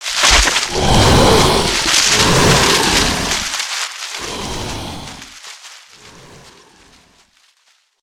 48d440e14c Divergent / mods / Soundscape Overhaul / gamedata / sounds / monsters / bloodsucker / swamp_runaway_1.ogg 70 KiB (Stored with Git LFS) Raw History